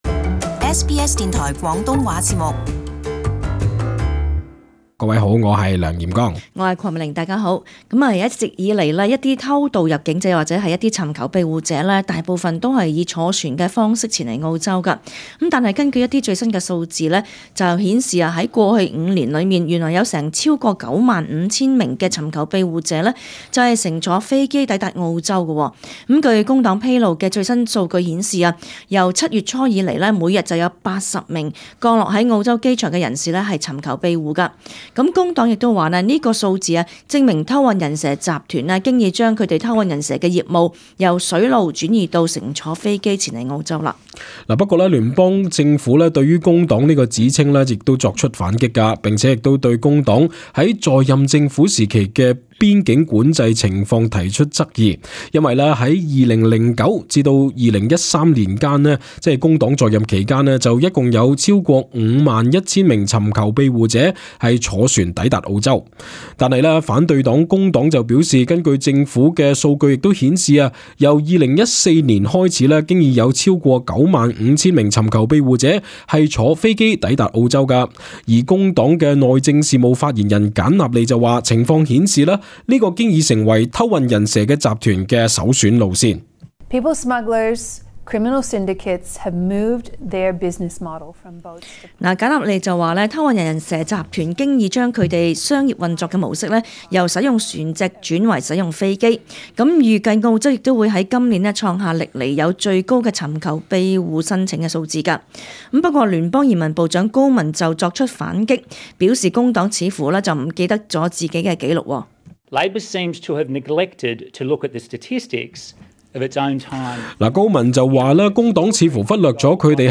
READ MORE 澳議員恐「被逐出」馬瑙斯島 難民醫療法案獲眾議院通過 【時事報導】政府收緊褔利或致過千尋求庇護者露宿街頭 瀏覽更多最新時事資訊，請登上 廣東話節目 Facebook 專頁 。